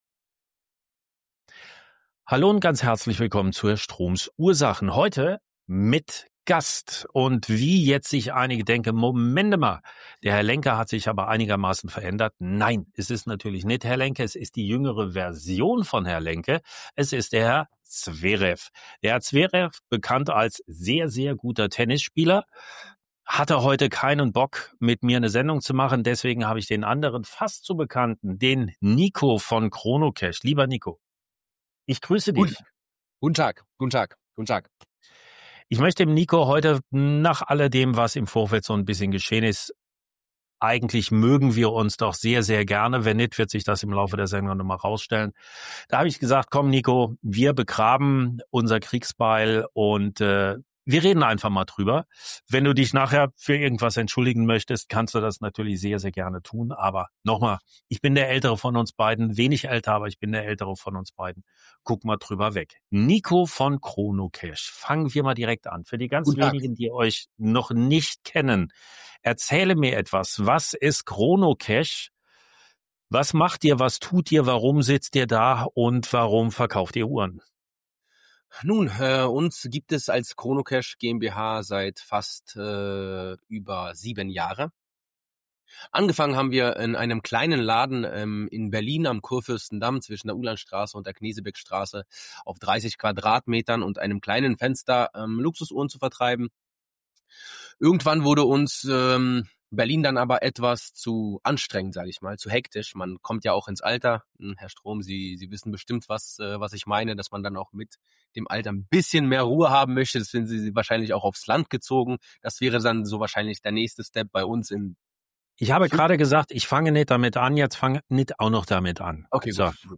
Unterhalten sich zwei Uhrenhändler.